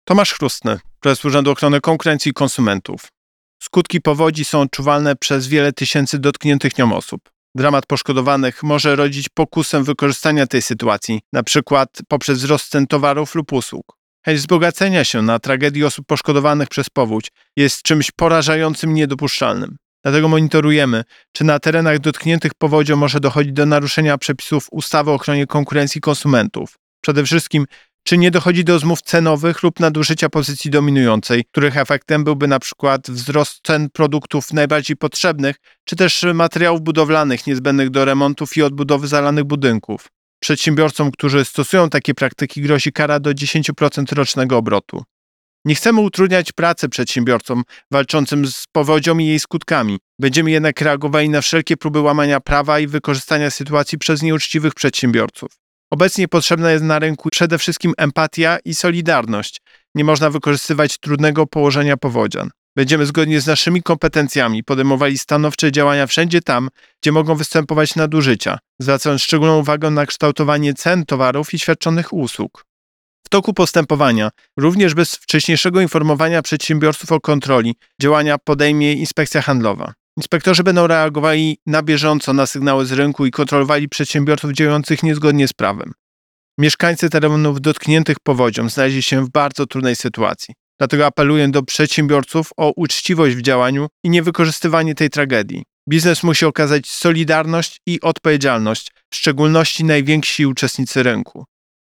Wypowiedź Prezesa UOKiK Tomasza Chróstnego Zgodnie z prawem zakazane są porozumienia przedsiębiorców zmierzające do ustalenia cen towarów lub usług.